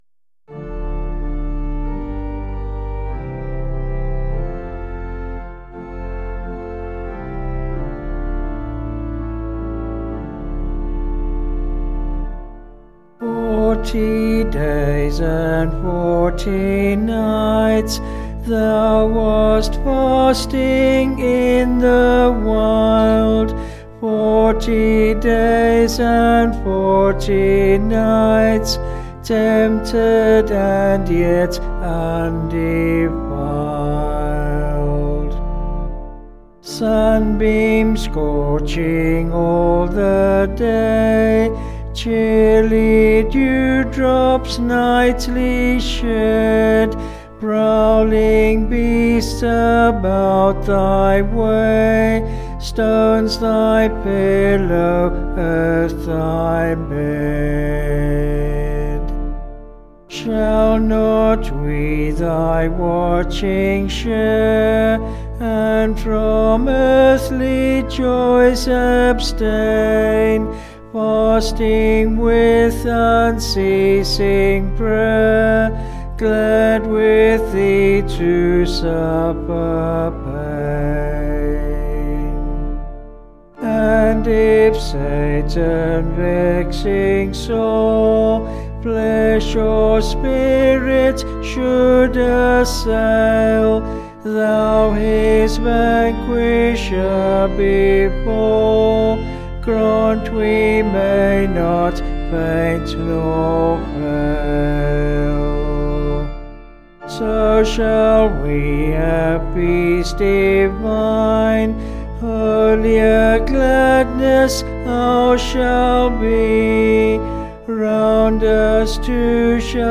6/Dm
Vocals and Organ   263.9kb Sung Lyrics